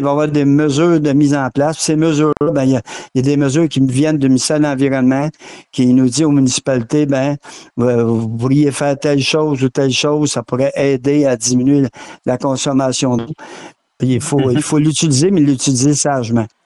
Une fois les données recueillies, le maire a expliqué ce qu’il va arriver.